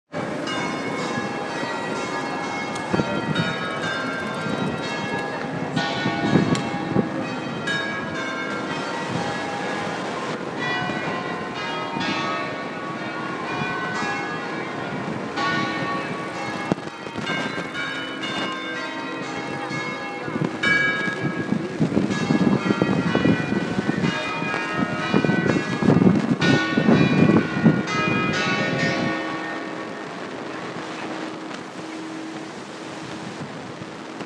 Bells of the Basilica in Loreto
Having a very small holiday in Marche, Italy: these are the bells of the Basilica in Loreto, one of the biggest Catholic pilgrimage sites.